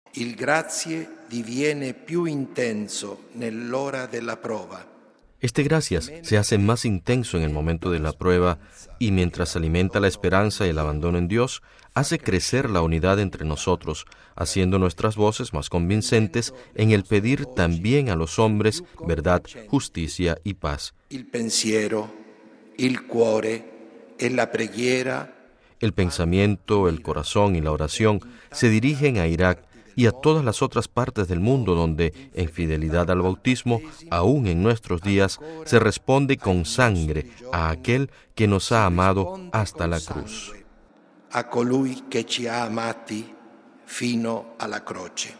Este cuestionamiento fue pronunciado, esta tarde, en la basílica vaticana por el cardenal Leonardo Sandri, prefecto de la Congregación para las Iglesias Orientales, en su homilía durante la celebración eucarística en sufragio por los sacerdotes y fieles víctimas del atentado del pasado 31 de octubre en la catedral de Bagdad, Irak